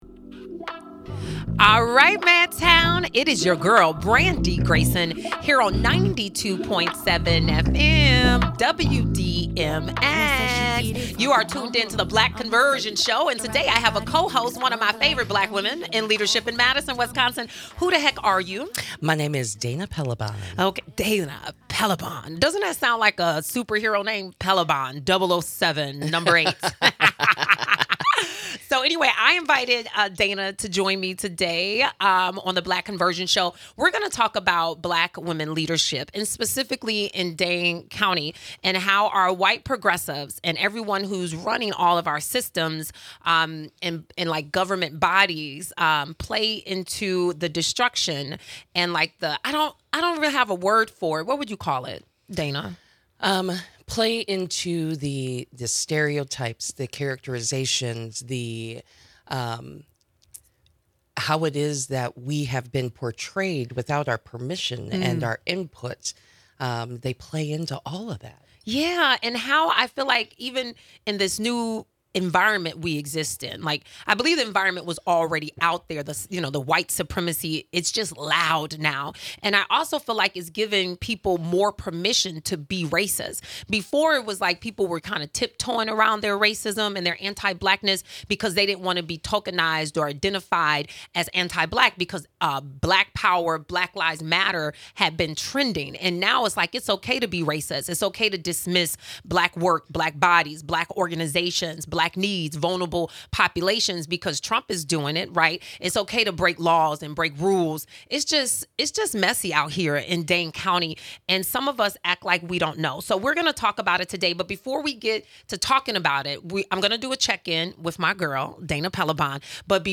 spoken word